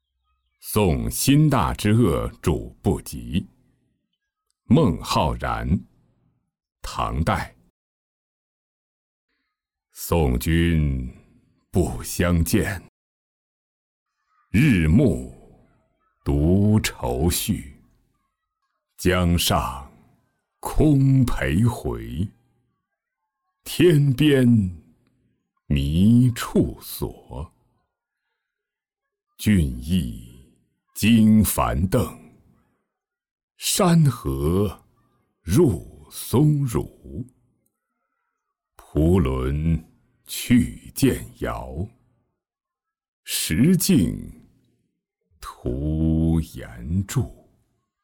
送辛大之鄂渚不及-音频朗读